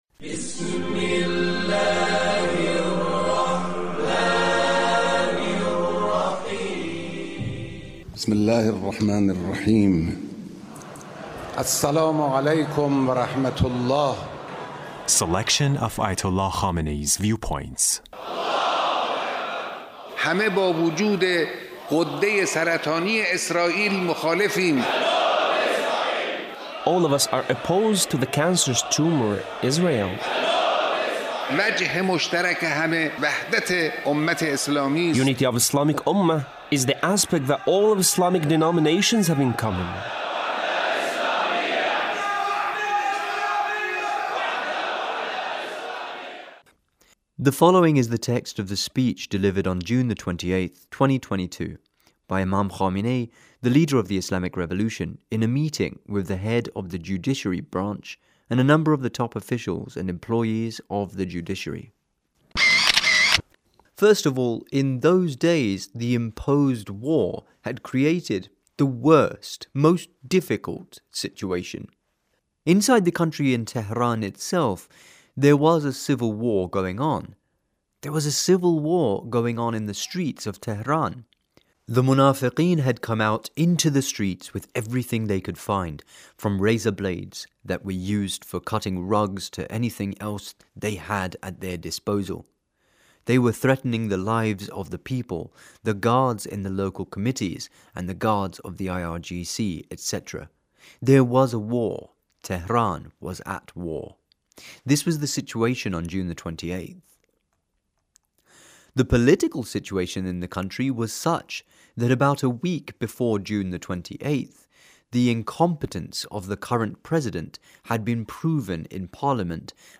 The Leader's speech in a meeting with a number of the top officials and employees of the Judiciary.